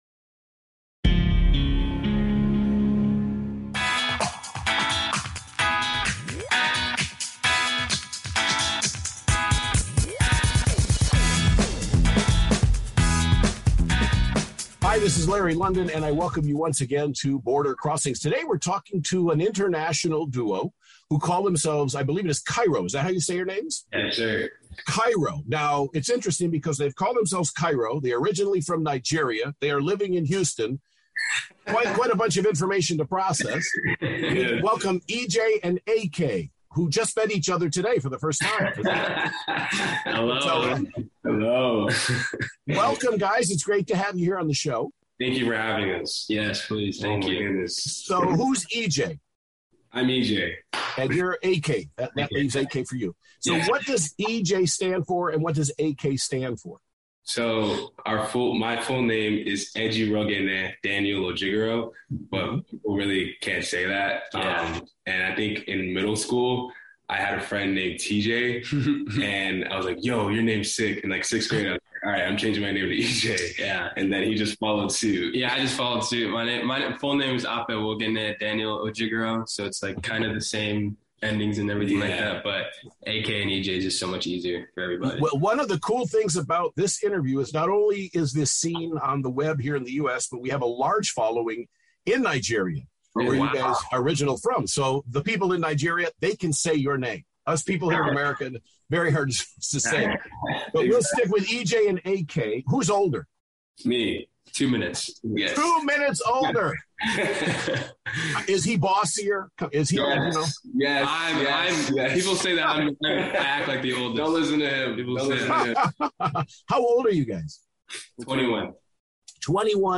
Houston-raised Pop / R&B duo